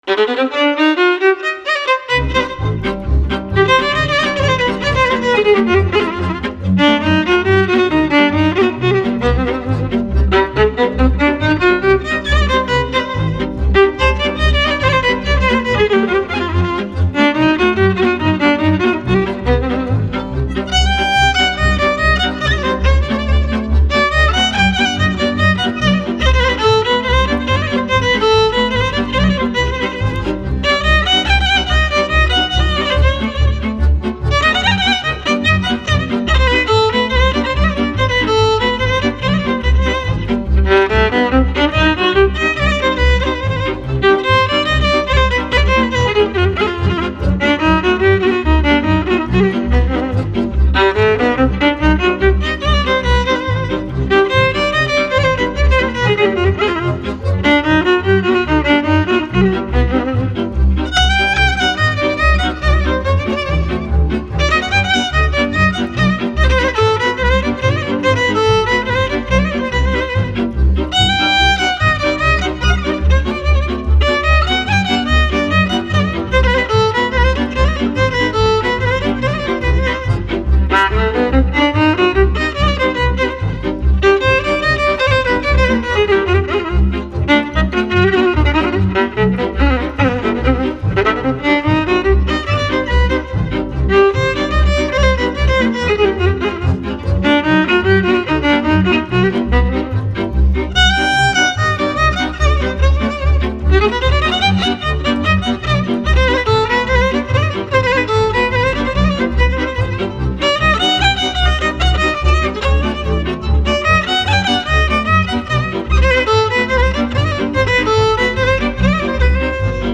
- realizează o bună omogenitate timbrală datorată celor trei instrumente din categoria “cu  coarde şi arcuş”
contrabasul  – frecvenţele grave
braciul cu trei coarde – frecvenţele medii
vioara – frecvenţele înalte
contrabasul - cântă fundamentalele acordurilor şi înfăptuieşte suportul ritmic de bază, pe timpii principali ai piesei
braciul - execută acordurile de trei sunete, pe timpi, pe diviziuni de timpi sau pe contratimpi